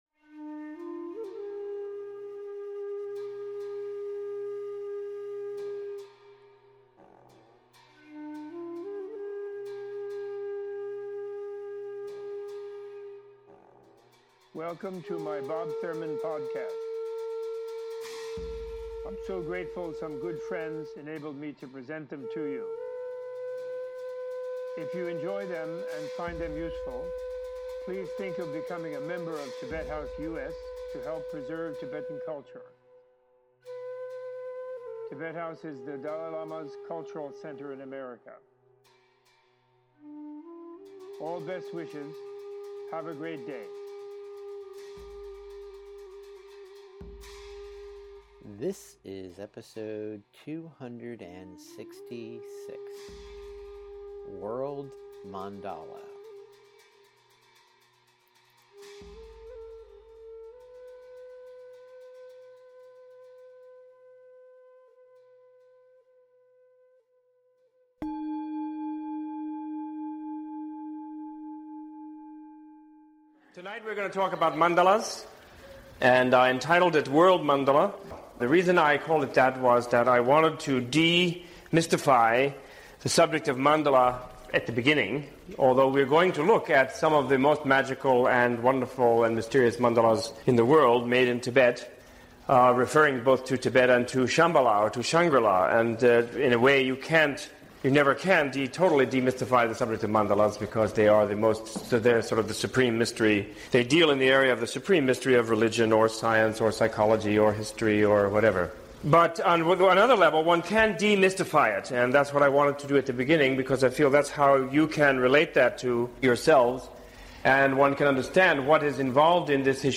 In this fast paced teaching recorded at the New York Open Center, Professor Thurman introduces the Buddha’s teachings through an exploration of Buddhist Mandalas and their counterparts across history and in Western culture. Opening with an overview of the architecture used in the design of Buddhist Tantric mandalas Robert Thurman uses personal stories, popular culture and world history to demystify the intricate tools of inner and outer transformation they contain.